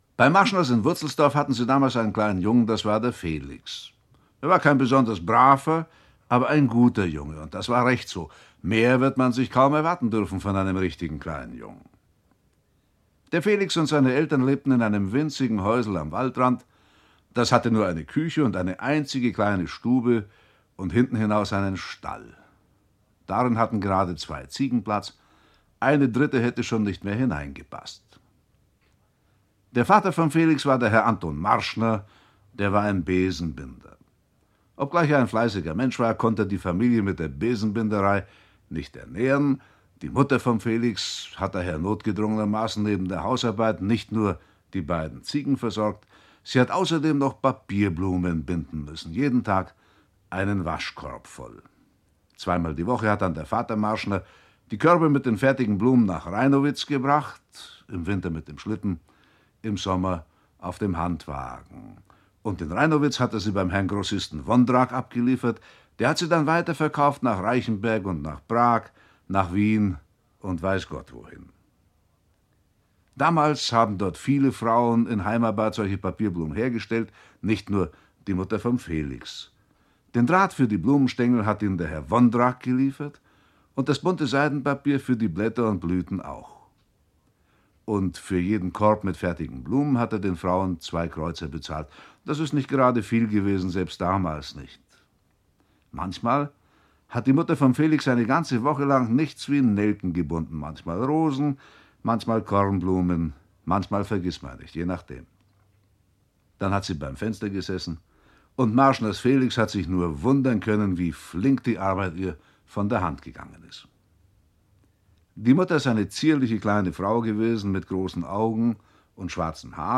Sechs Weihnachtsgeschichten. Ungekürzte Autorenlesung mit Musik (2 CDs)
Otfried Preußler (Sprecher)
Ein vergnüglicher Weihnachtszauber für die ganze Familie - gelesen vom beliebten Kinderbuchautor Otfried Preußler selbst!